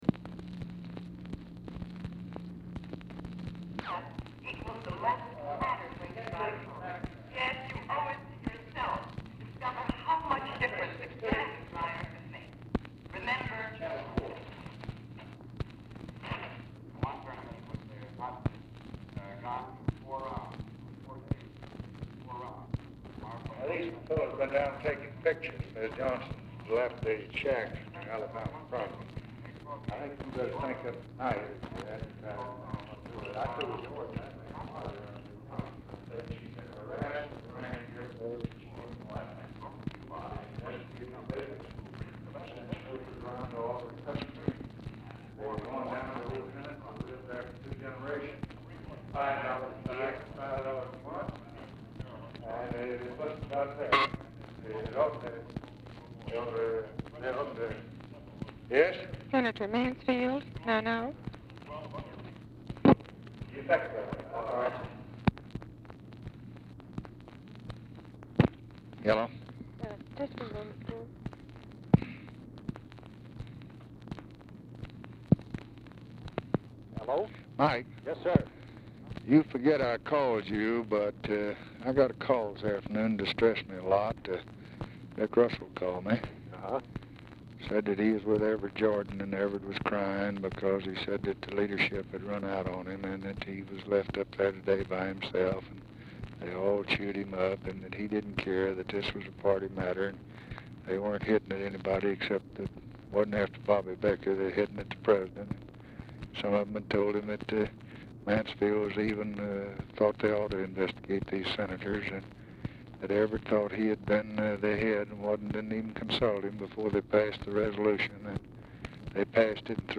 Telephone conversation # 3448, sound recording, LBJ and MIKE MANSFIELD, 5/13/1964, 6:28PM
OFFICE CONVERSATION PRECEDES CALL; TV AUDIBLE IN BACKGROUND; CONTINUES ON NEXT RECORDING
Format Dictation belt